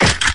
equip.mp3